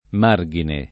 [ m # r g ine ]